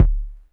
kick01.wav